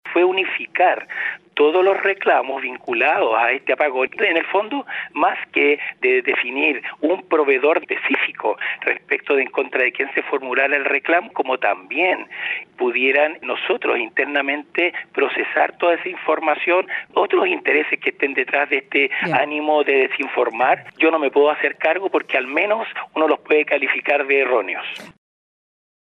Frente a las palabras de estos correos, en conversación con el Expreso Bío Bío, el director del Sernac, Andrés Herrera, las calificó como “declaraciones poco felices, quizás”.